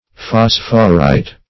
Phosphorite \Phos"phor*ite\, n.